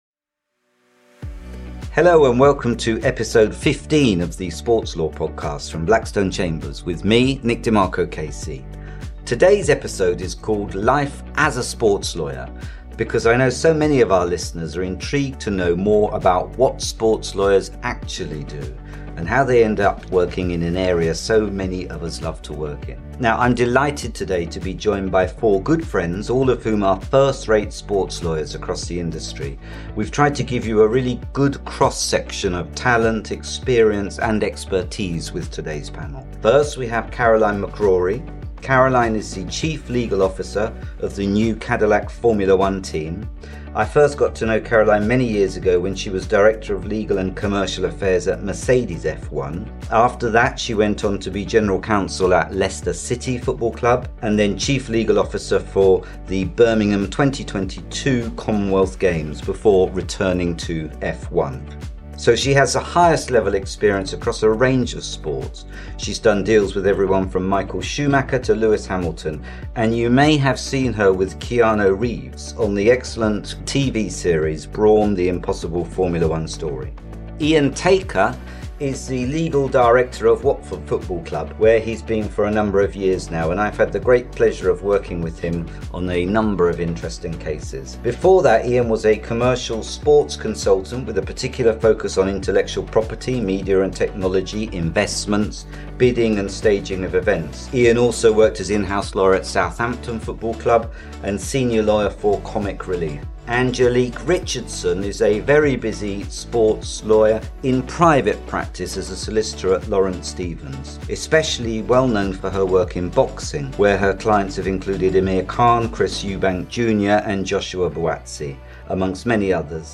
The panel explores the varied work that sports lawyers undertake, their favourite aspects of the job, and the paths each of them took to reach their current roles. They conclude by sharing their top tips for anyone aspiring to work in sports law.